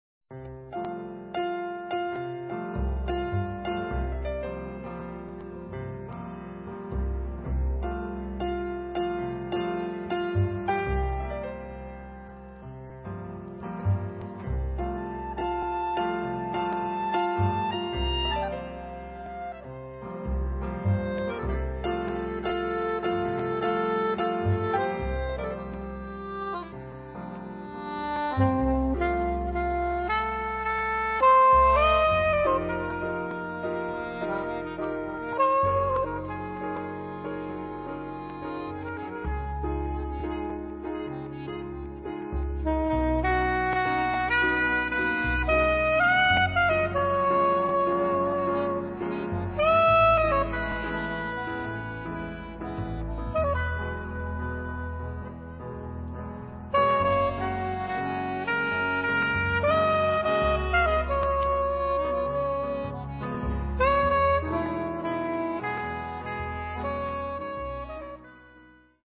sax
fisarmonica
piano
contrabbasso